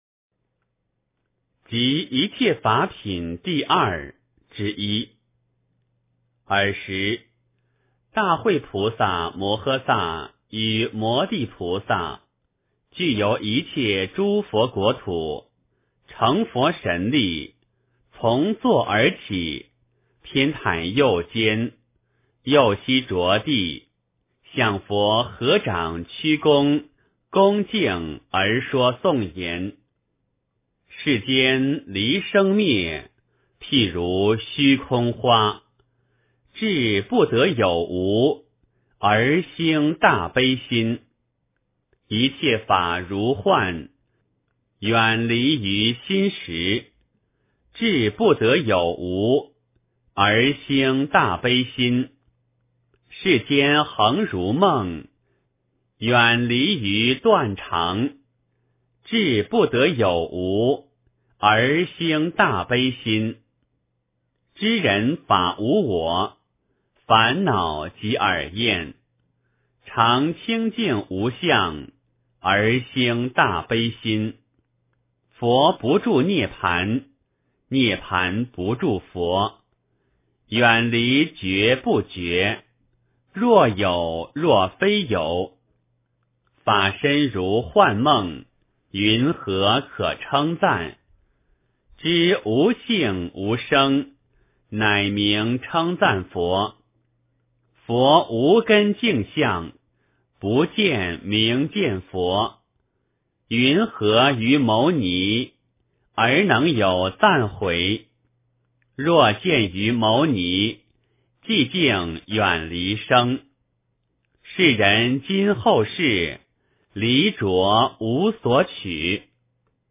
楞伽经（一切法品第二之一） 诵经 楞伽经（一切法品第二之一）--未知 点我： 标签: 佛音 诵经 佛教音乐 返回列表 上一篇： 金刚般若波罗蜜经 下一篇： 楞伽经（一切法品第二之三） 相关文章 Ney Ogmin Chying Podrang--琼英卓玛 Ney Ogmin Chying Podrang--琼英卓玛...